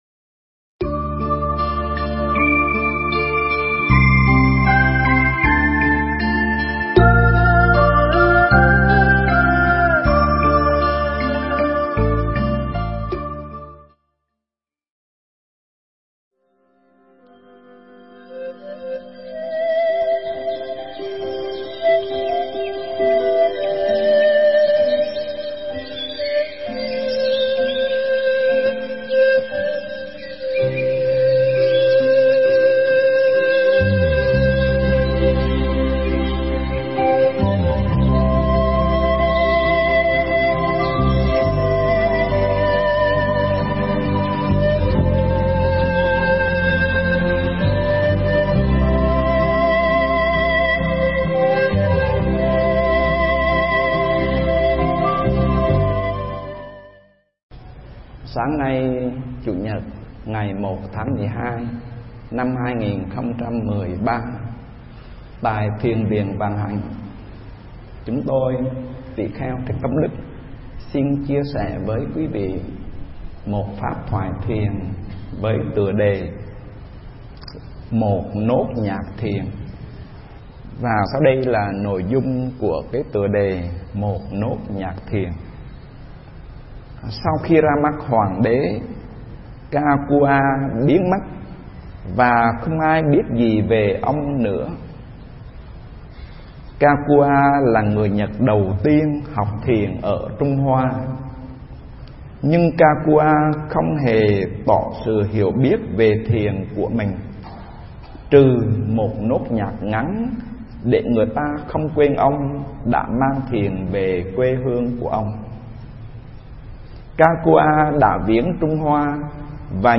Nghe Mp3 thuyết pháp Một Nốt Nhạc Thiền